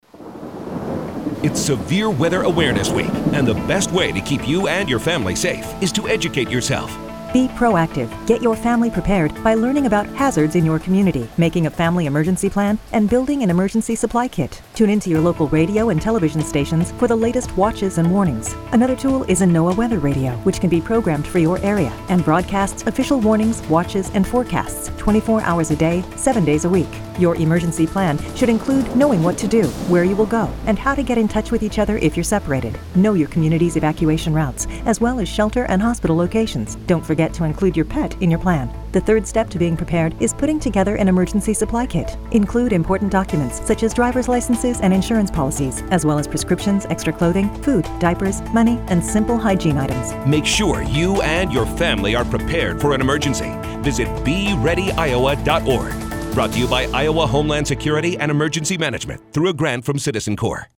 PSA_SWAW_FamPrep.mp3